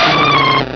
Cri de Cadoizo dans Pokémon Rubis et Saphir.